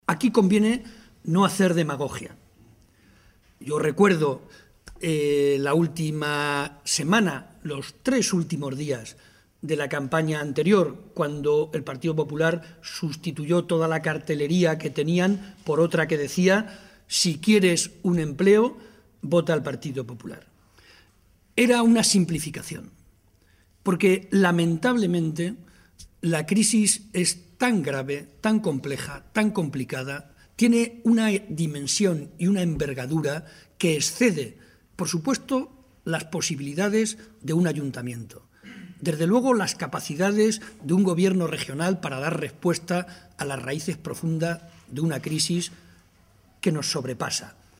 Momento de la rueda de prensa.